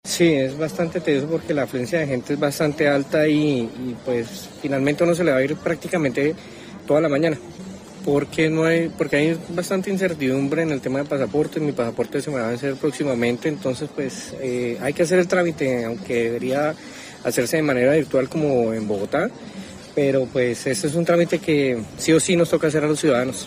Ciudadano que participa de las largas filas en Santander para expedir pasaporte